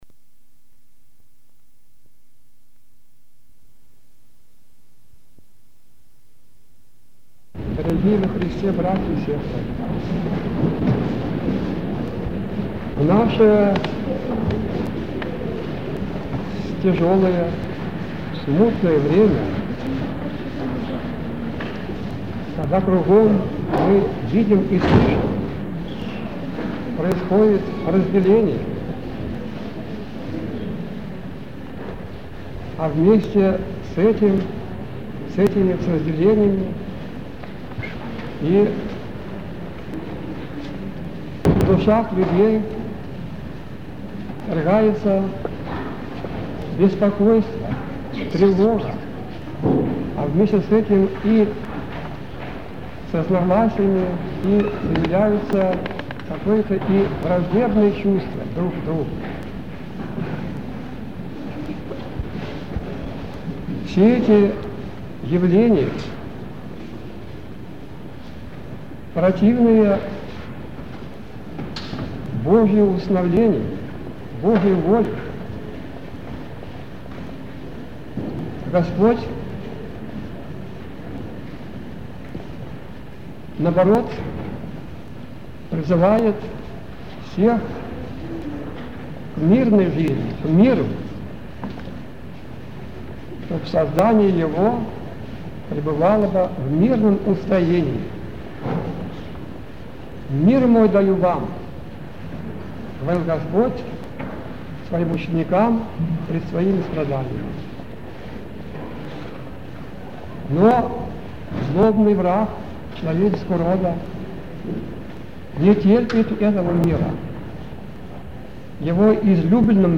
Живой голос старца (аудио)
24 проповеди архимандрита Кирилла (Павлова)